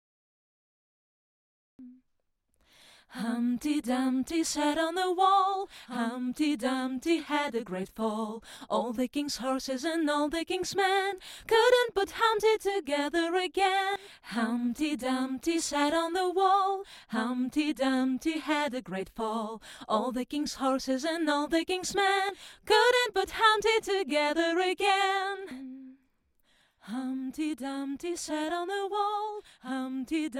The singer is not a native English speaker. Can you hear her accent?